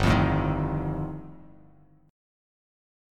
G7sus2#5 chord